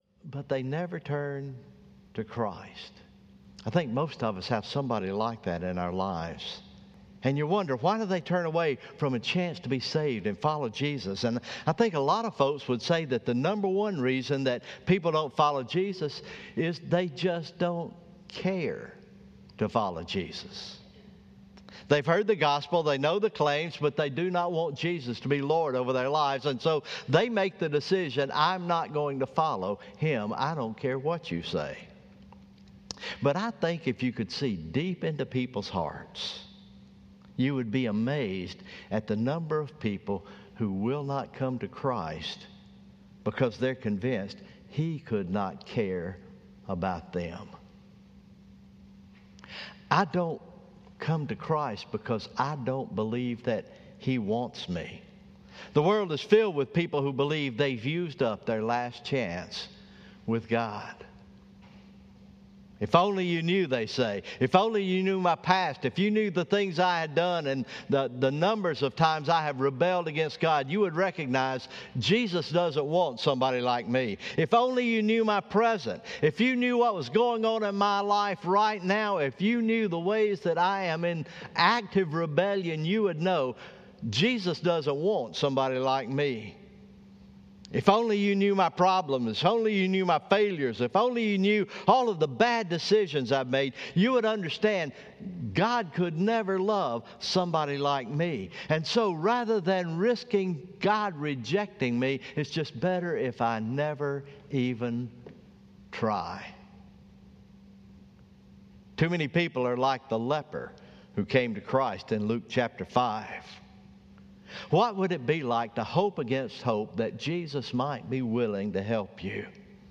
Morning Worship